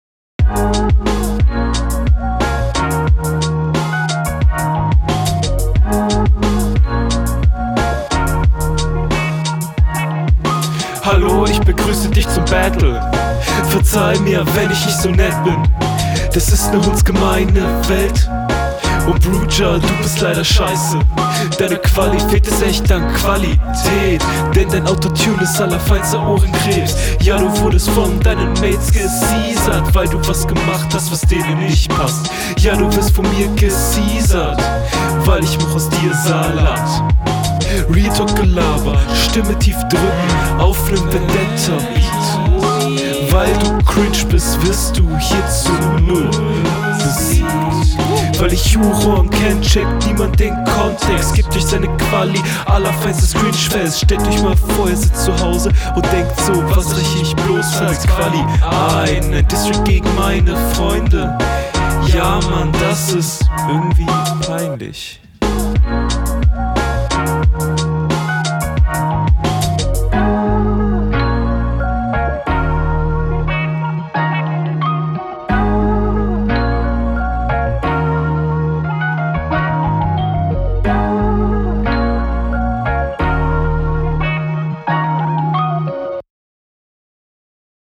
Flow ist angenehm aber an einigen Stellen komisch.
Beat ist nice, Oldschool. Stimme und Einsatz eigentlich okay aber leider etwas abgehakt.